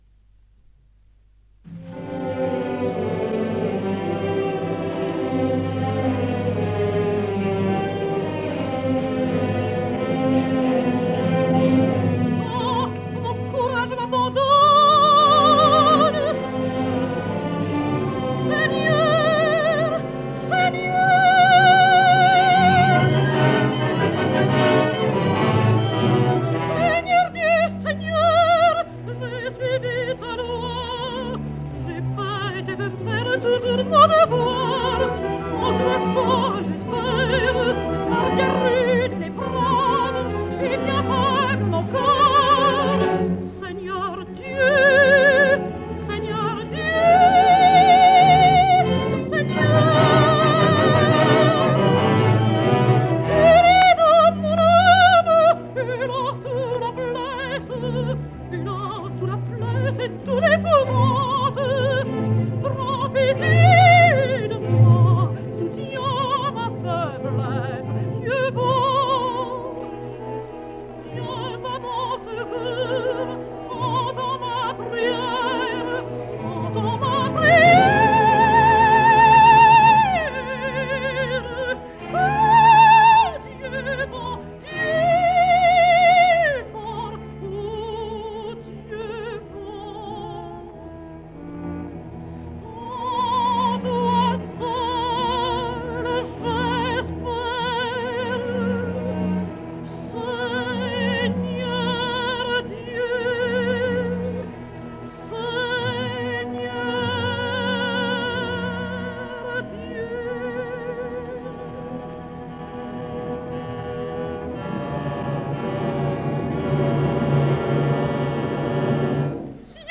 I finalment la pregària, amb una tensió harmònica que denota amb frases trencades, la pèrdua de control del personatge.